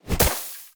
Sfx_creature_pinnacarid_hop_slow_04.ogg